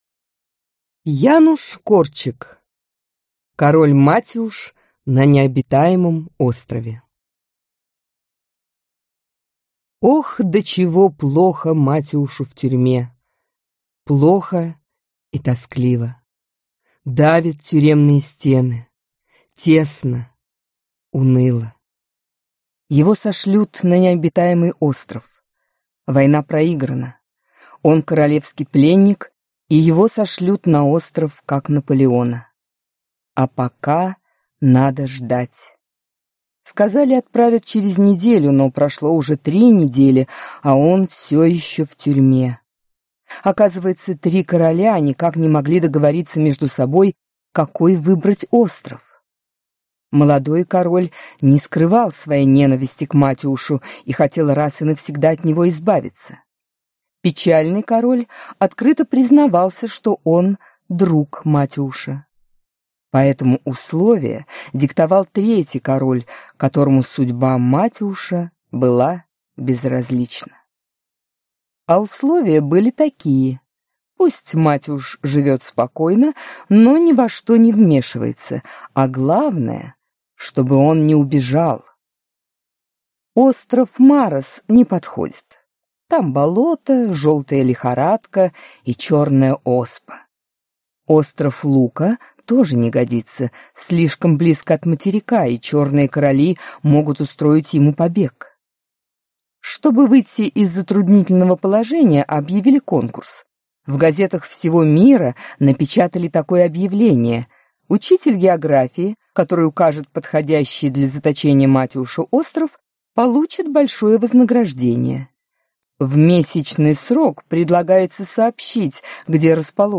Аудиокнига Король Матиуш на необитаемом острове | Библиотека аудиокниг
Прослушать и бесплатно скачать фрагмент аудиокниги